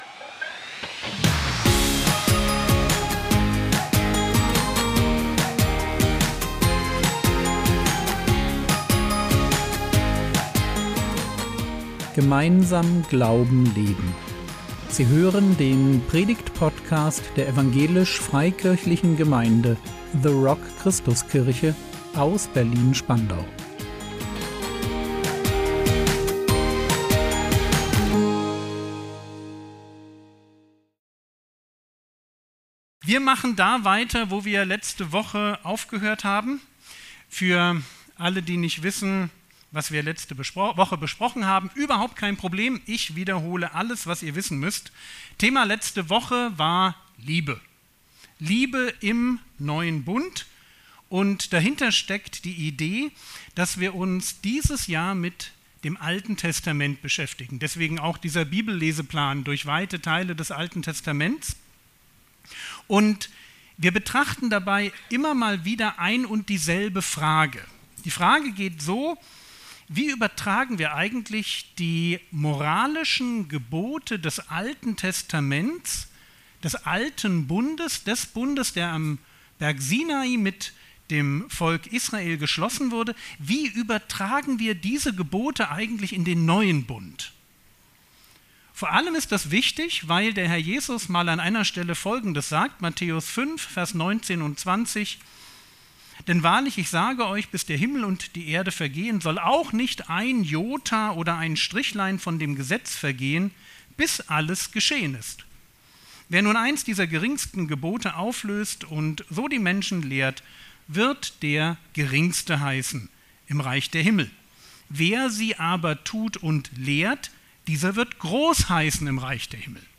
Liebe leben im Neuen Bund (2) | 06.07.2025 ~ Predigt Podcast der EFG The Rock Christuskirche Berlin Podcast